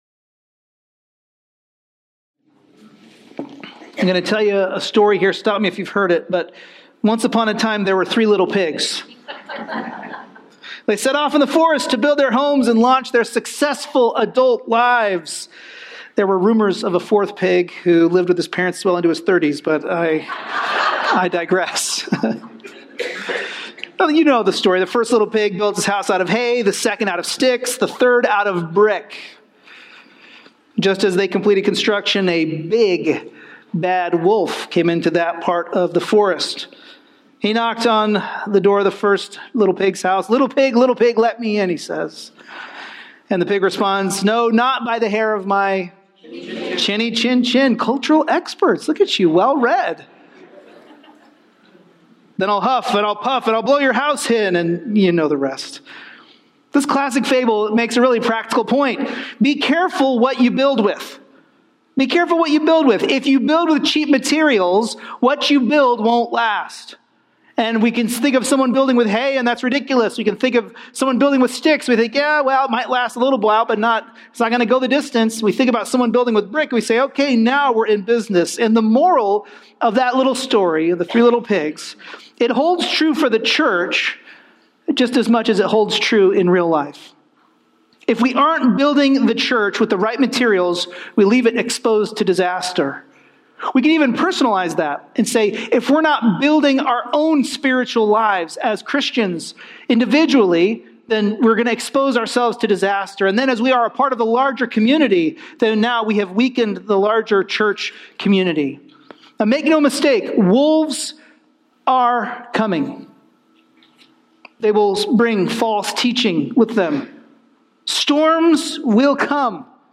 An expositional preaching series through Paul's first letter to the church at Corinth.